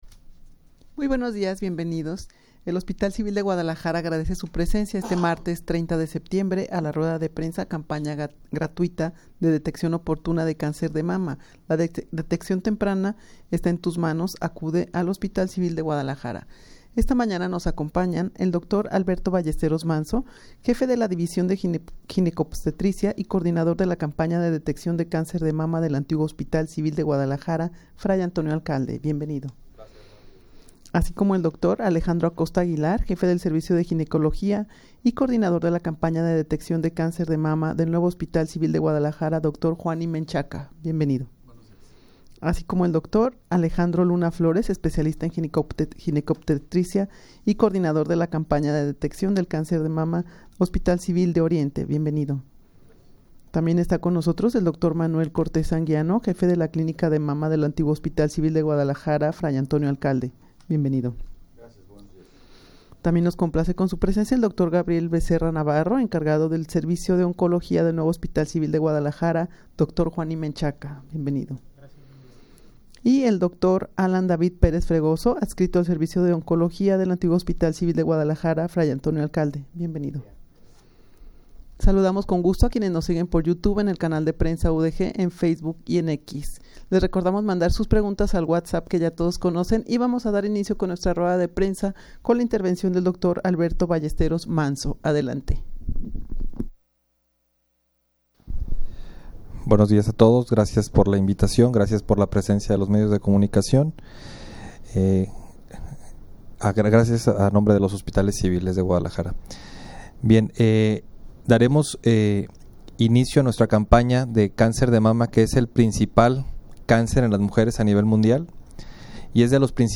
Audio de la Rueda de Prensa
rueda-de-prensa-campana-gratuita-de-deteccion-oportuna-de-cancer-de-mama.mp3